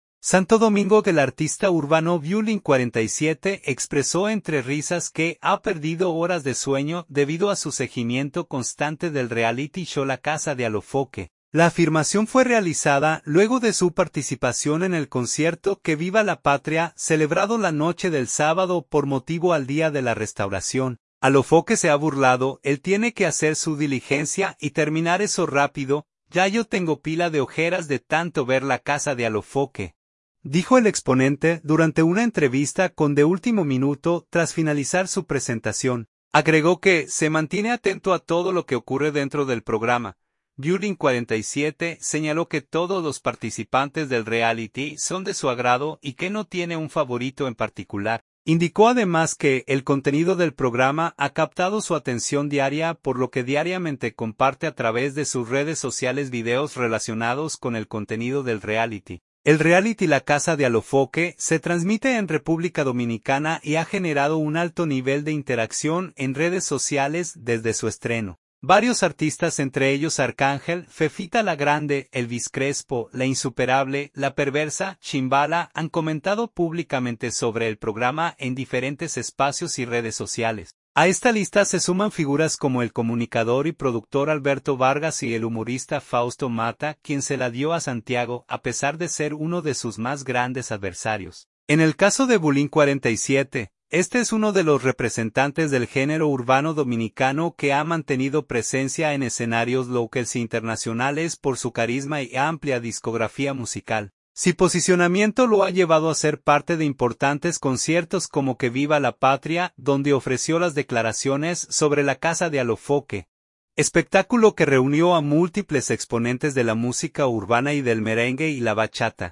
Santo Domingo.- El artista urbano Bulin 47 expresó entre risas que ha perdido horas de sueño debido a su seguimiento constante del reality show La Casa de Alofoke.
“Alofoke se ha burlado, él tiene que hacer su diligencia y terminar eso rápido, ya yo tengo pila de ojeras de tanto ver La Casa de Alofoke”, dijo el exponente durante una entrevista con De Último Minuto, tras finalizar su presentación.